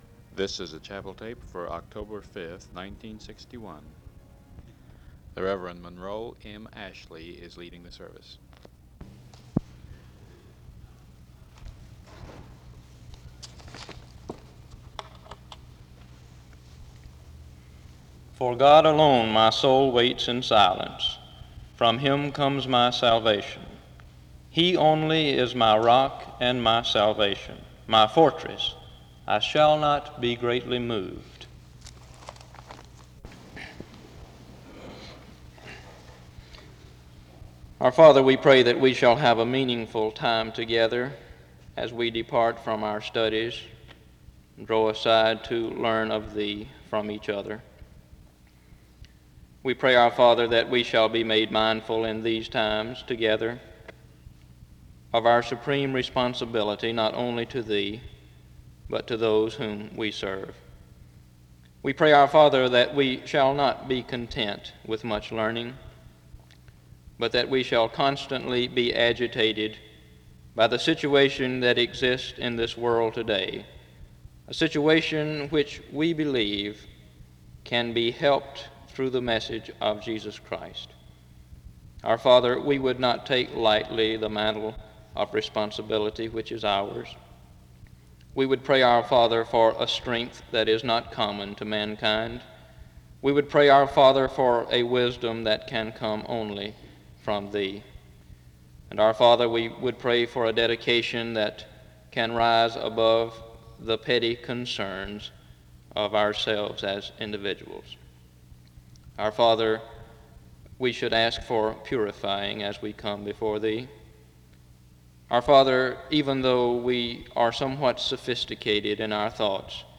The service opens with a scripture reading from 0:18-0:31. A prayer is offered from 0:37-2:42.